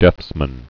(dĕthsmən)